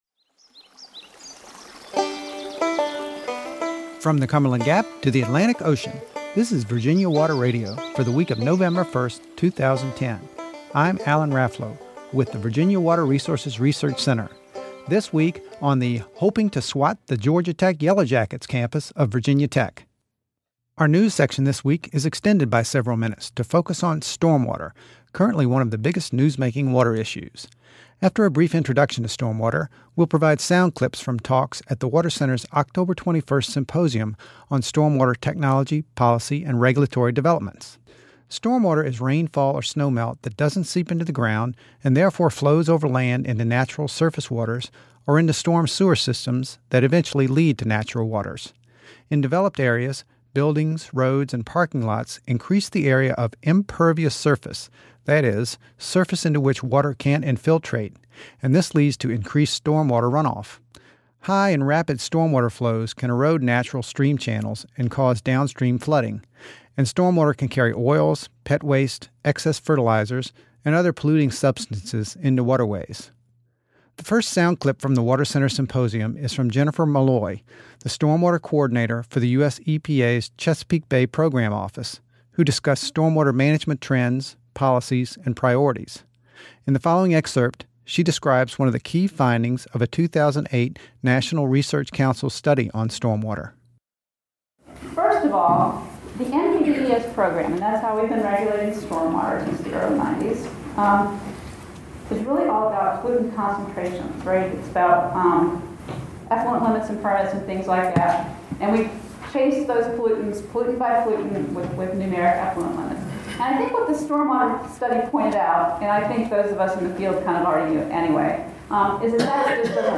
Our show presents sounds and music that relate to Virginia’s waters, from the Cumberland Gap to the Atlantic Ocean.